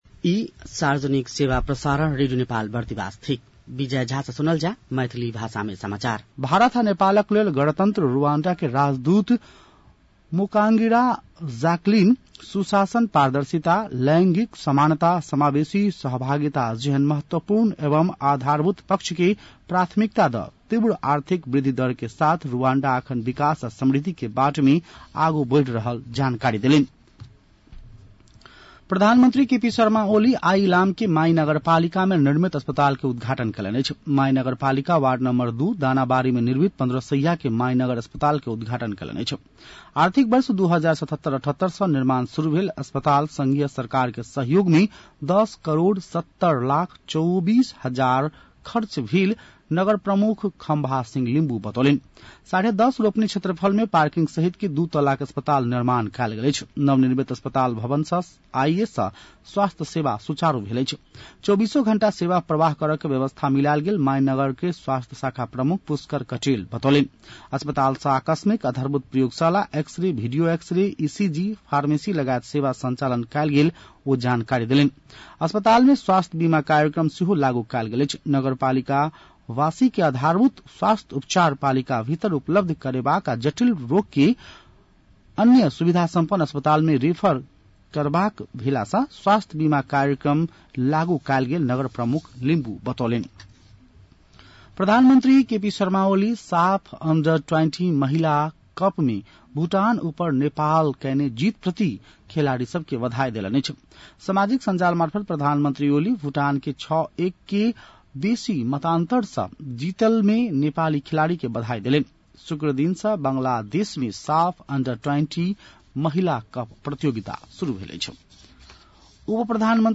मैथिली भाषामा समाचार : २८ असार , २०८२